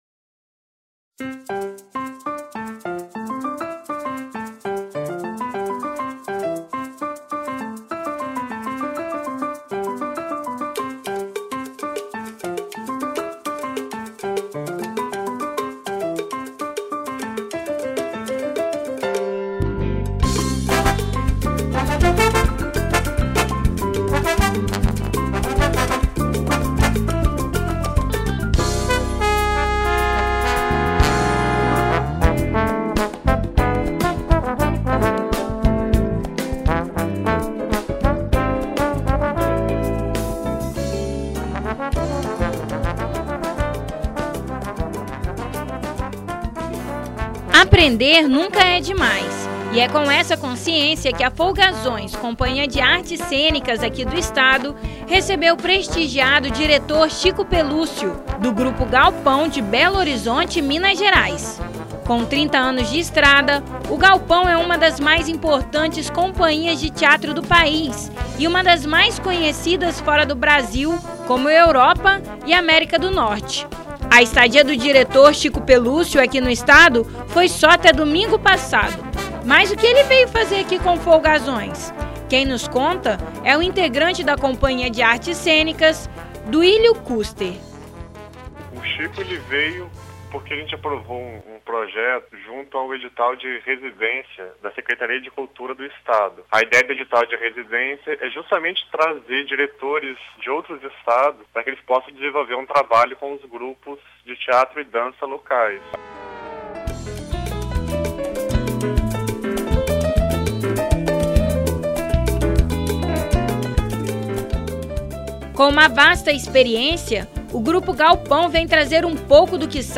Confira a entrevista que o Revista Universitária.